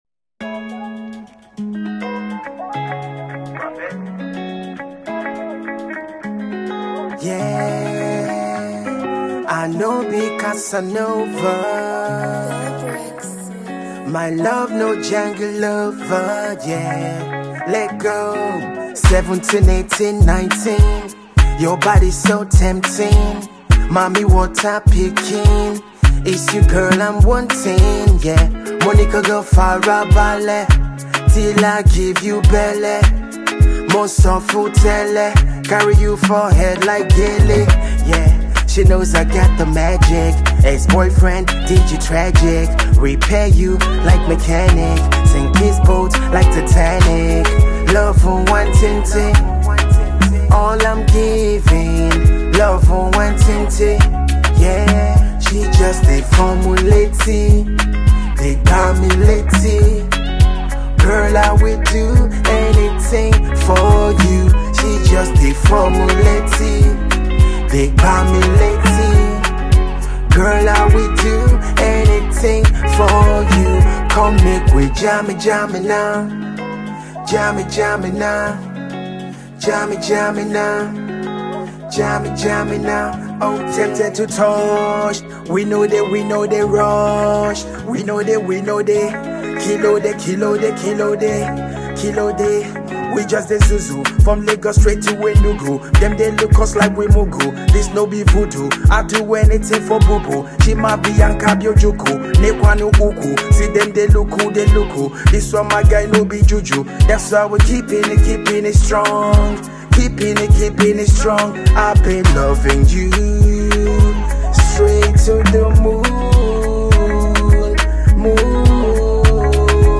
reggae Dancehall